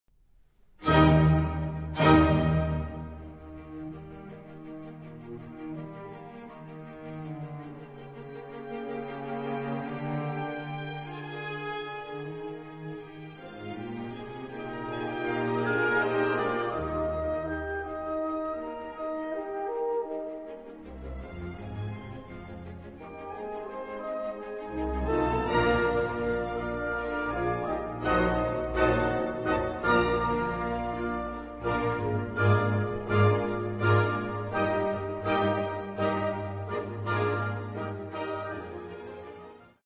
Symphony No. 3 in E flat Op. 55